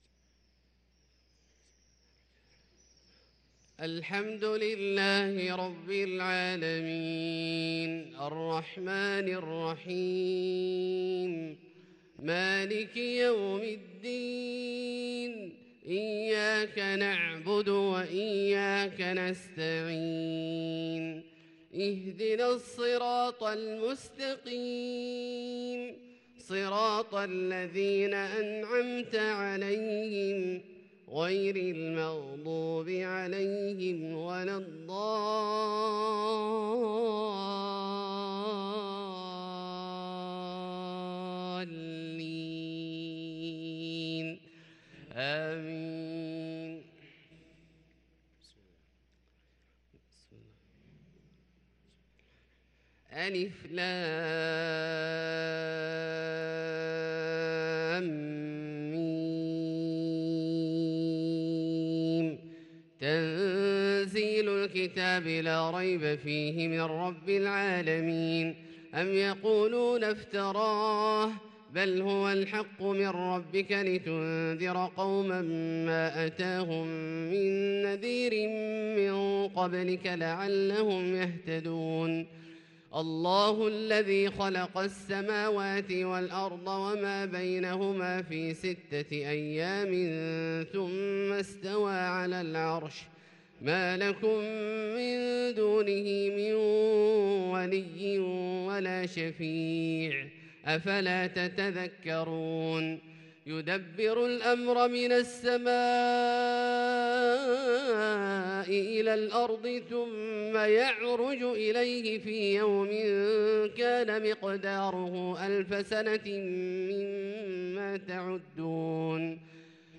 صلاة الفجر للقارئ عبدالله الجهني 29 جمادي الأول 1444 هـ
تِلَاوَات الْحَرَمَيْن .